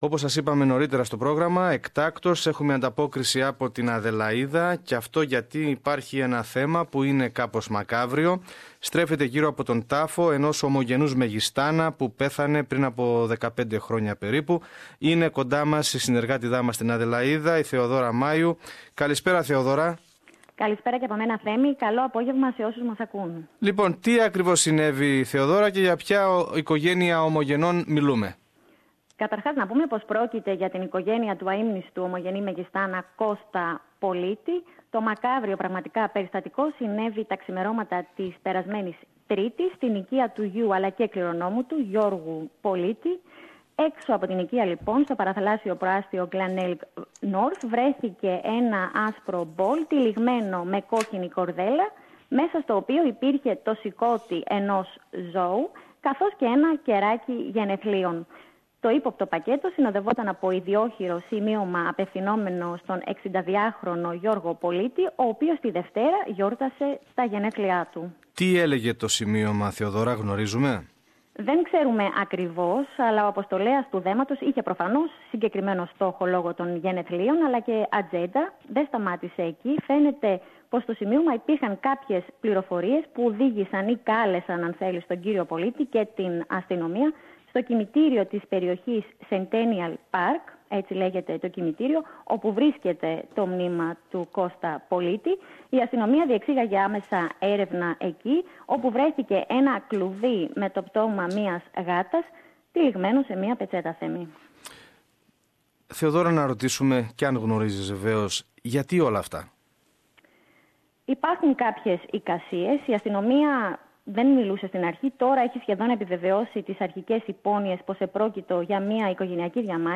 More in this report by our stringer